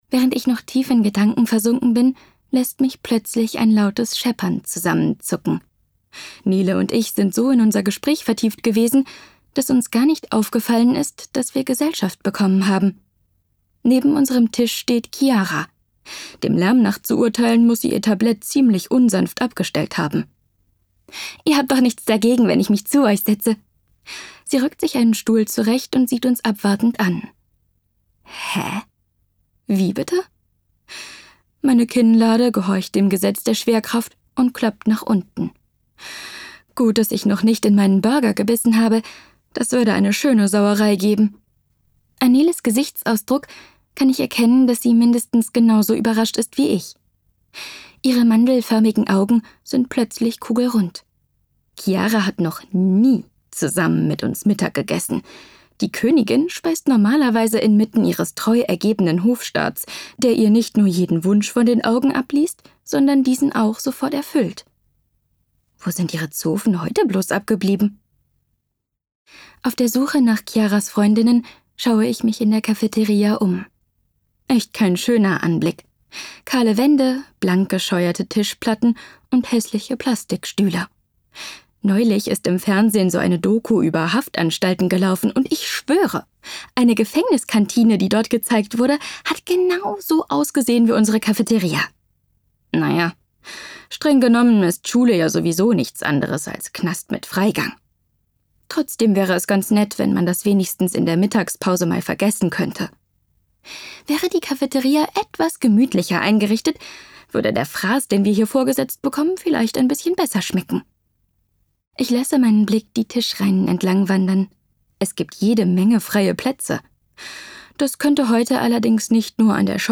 Mein Sommer voller Flips und Flops Zuckersüße Liebesgeschichte über Freundschaft, Selbstfindung und das ganz große Herzklopfen!. Ungekürzt.